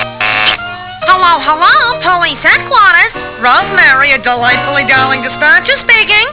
Rosemary, the telephone operator, answers a call. WAV 49K 6 Seconds